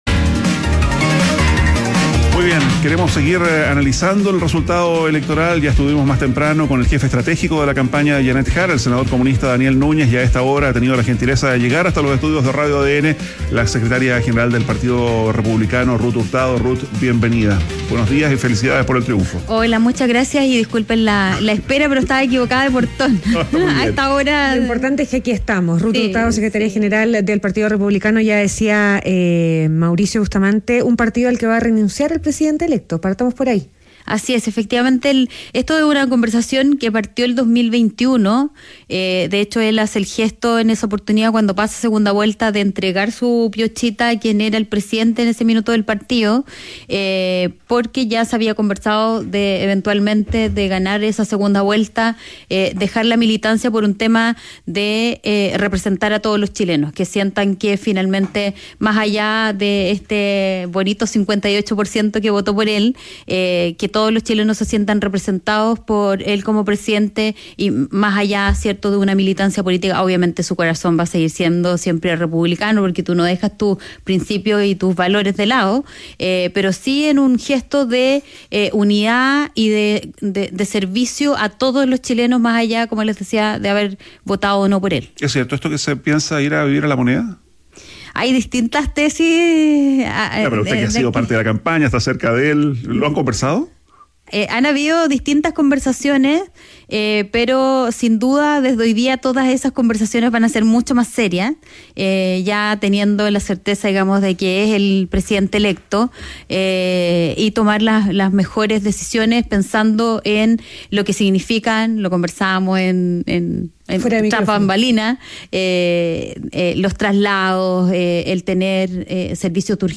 ADN Hoy - Entrevista a Ruth Hurtado, secretaria general del Partido Republicano